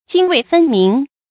成语繁体 涇渭分明 成语简拼 jwfm 成语注音 ㄐㄧㄥ ㄨㄟˋ ㄈㄣ ㄇㄧㄥˊ 常用程度 常用成语 感情色彩 中性成语 成语用法 主谓式；作主语、谓语、宾语、定语；含褒义 成语结构 主谓式成语 产生年代 古代成语 成语正音 泾，不能读作“jìnɡ”。